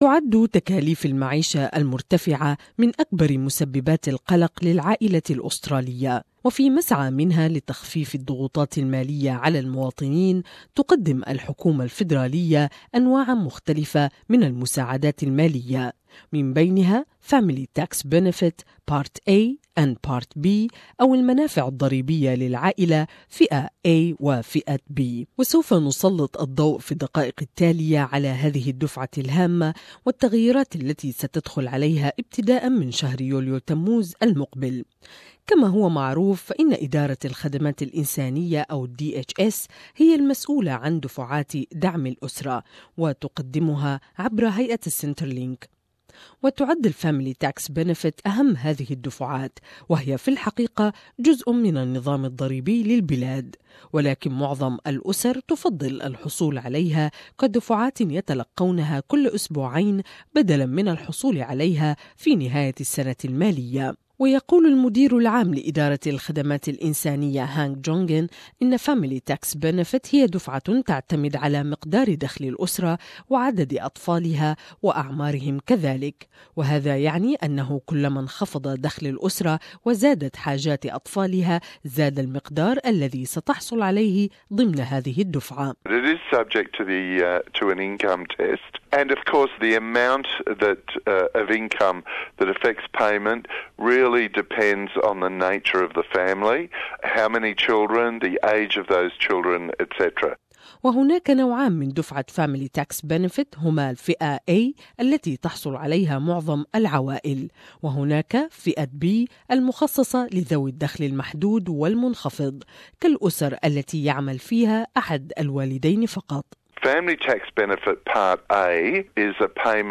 Do you know if you're eligible, how you could access it and what changes are coming from 1 July? More in this report